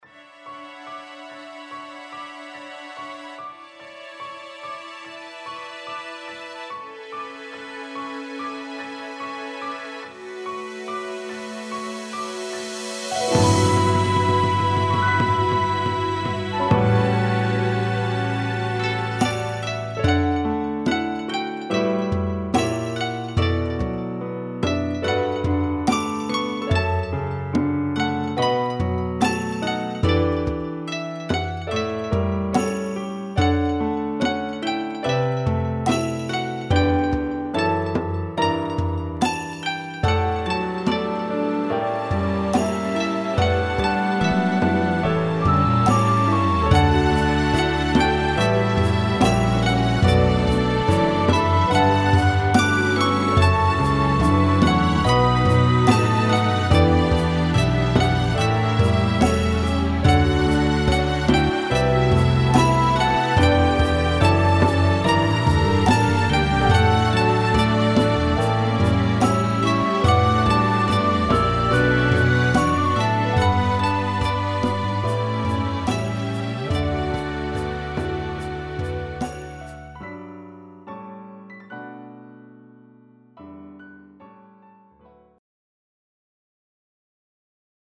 BGM试听: